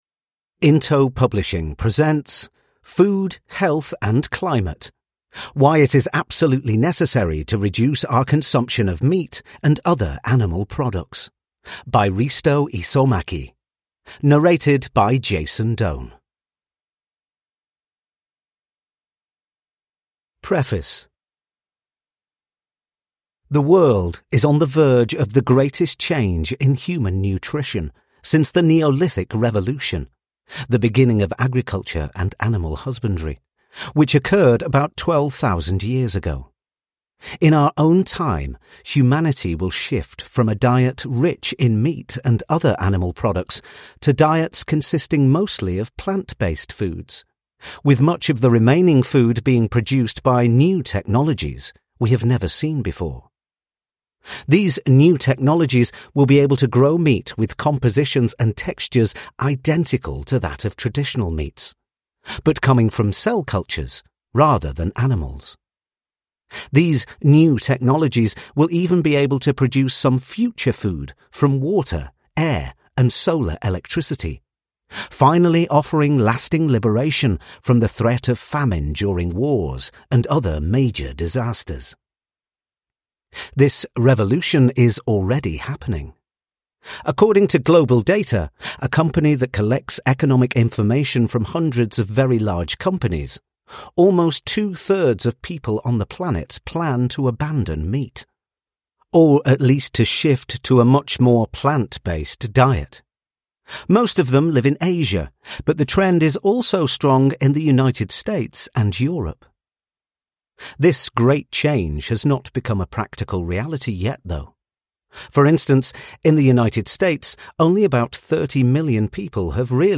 Food, Health and Climate – Ljudbok